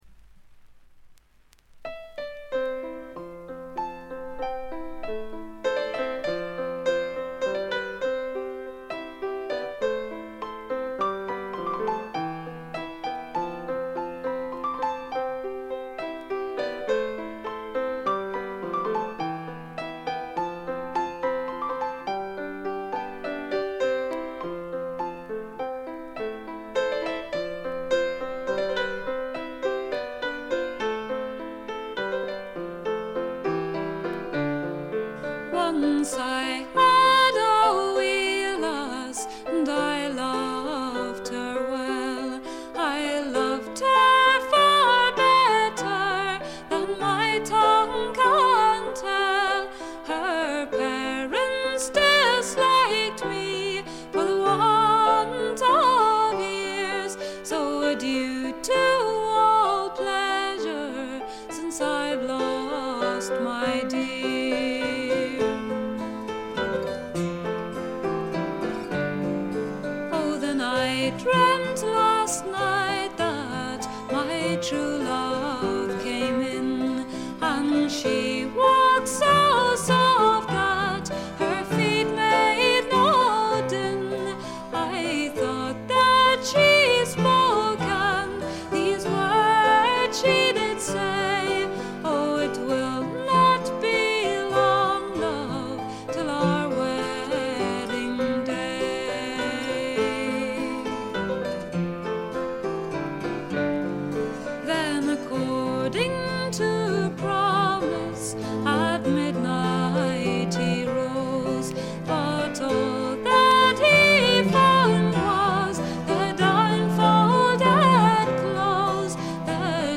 試聴曲は現品からの取り込み音源です。
Cello
Low Whistle
Vocals, Piano, Acoustic Guitar